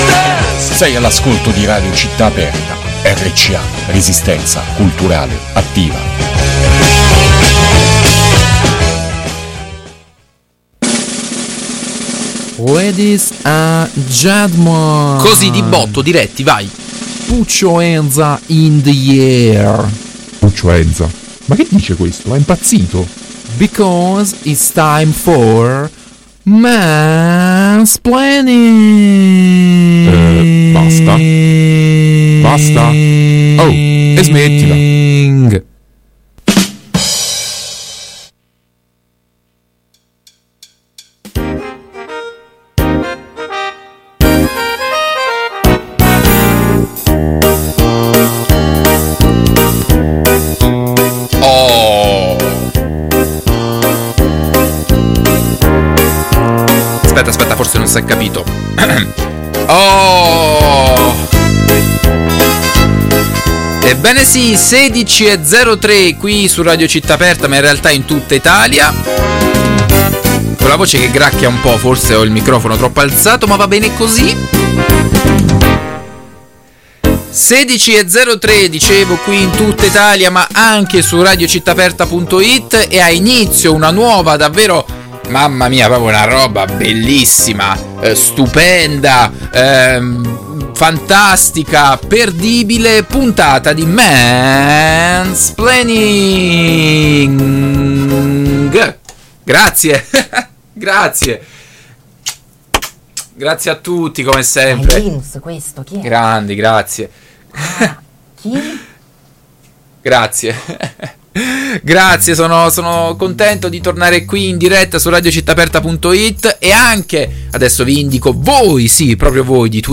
Ennesima identica puntata di “Mansplaining”, programma caruccio in onda su Radio Città Aperta.
No, aspetta, era: molta bella musica, nessuna chiacchera evitabile e tanti argomenti interessanti.